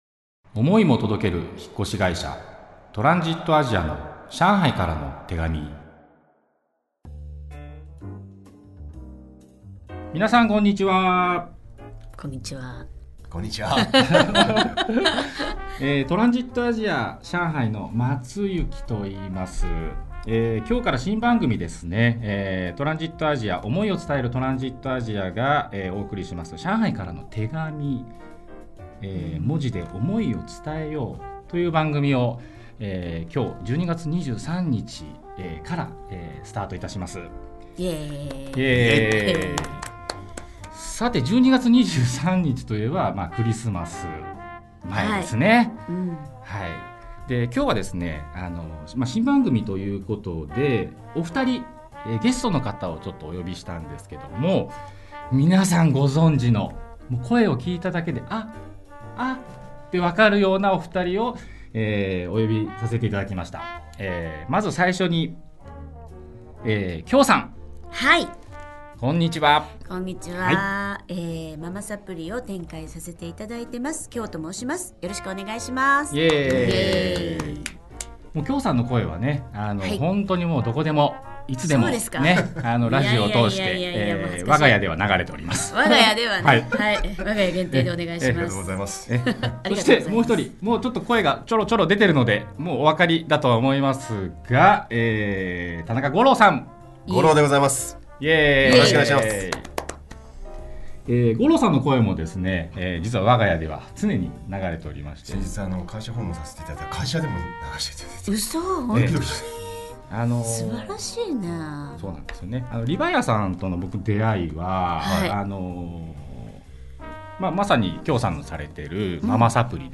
（共演させて頂いた大先輩と収録スタジオにて） 【番組予告】 12月23日配信予定の第１回目はLivAiAさんパーソナリティー大御所のあのお二方（？）にお越しいただき、番組テーマである「手紙」についてトークいたしました。